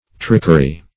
Trickery \Trick"er*y\, n.